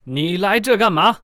文件 文件历史 文件用途 全域文件用途 Kg_tk_01.ogg （Ogg Vorbis声音文件，长度1.2秒，116 kbps，文件大小：17 KB） 源地址:地下城与勇士游戏语音 文件历史 点击某个日期/时间查看对应时刻的文件。 日期/时间 缩略图 大小 用户 备注 当前 2018年5月13日 (日) 02:13 1.2秒 （17 KB） 地下城与勇士  （ 留言 | 贡献 ） 分类:卡坤 分类:地下城与勇士 源地址:地下城与勇士游戏语音 您不可以覆盖此文件。